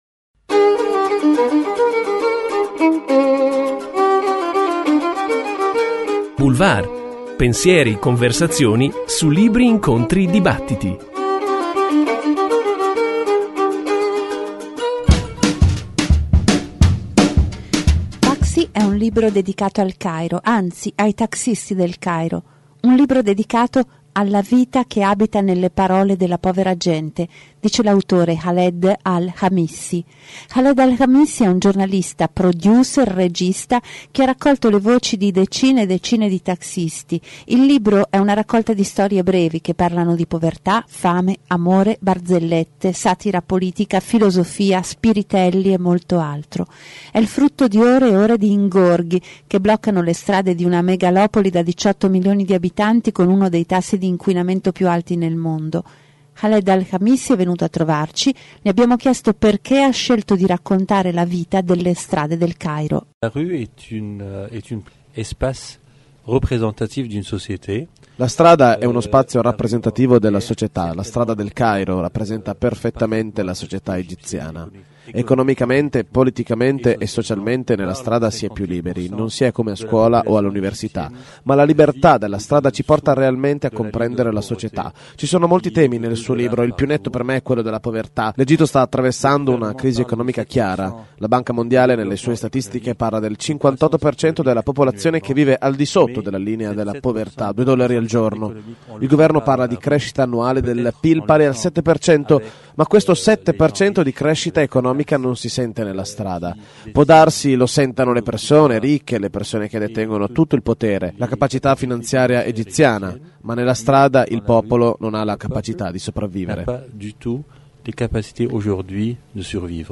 Khaled Al Khamissi è venuto nei nostri studi di passaggio da Bologna. Ha scelto di raccontare la vita della strada perché lo considera uno spazio rappresentativo della società.